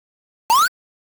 jump2.ogg